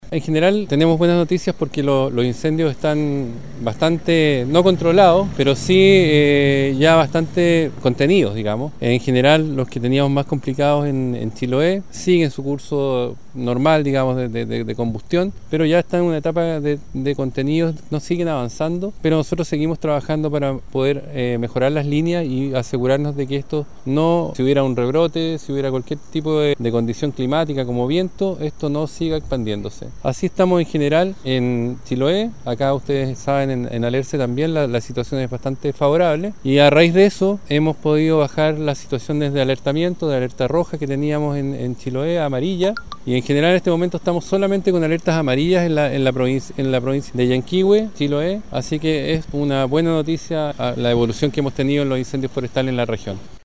El director regional de Conaf Jorge Aichele, señaló que en la actualidad sólo se mantienen cuatro comunas con alerta amarilla.